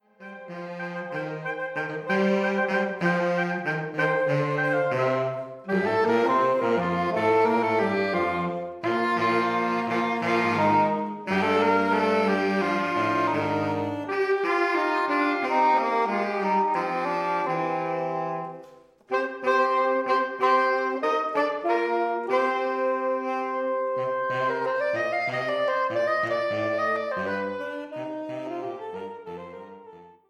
Quite a different sound from a ceilidh band, but saxes can play folk tunes with the best of them – whether that’s a soulful ballad or a lively jig to set your toe tapping!